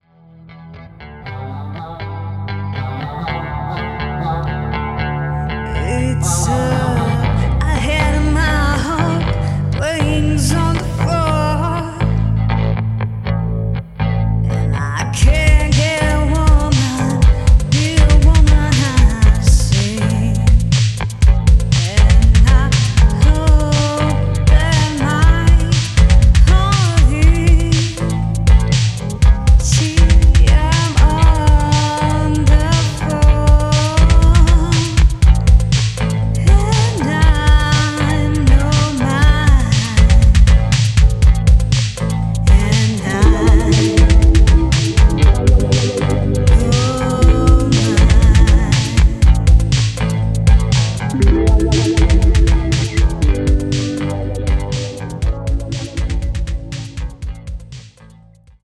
Portuguese Female Voiceover
Music & Singing